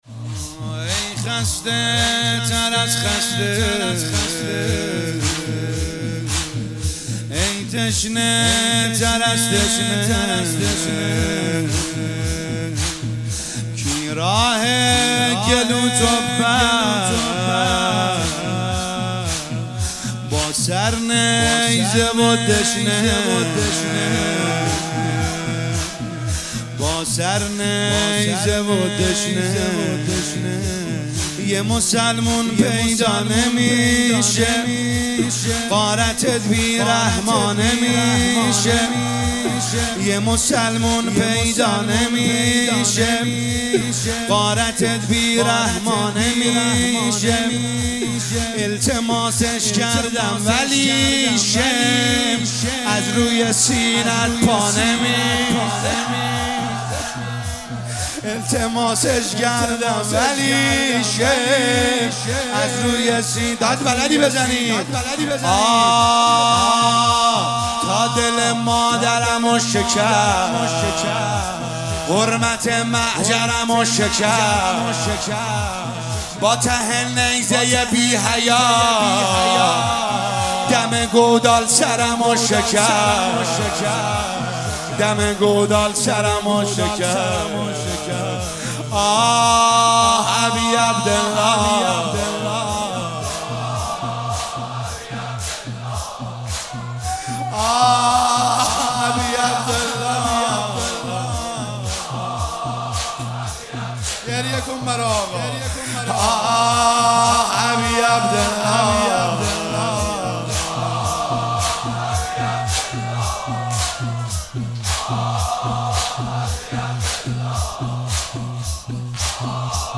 شب عاشورا محرم1401